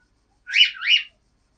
bip_03.mp3.mp3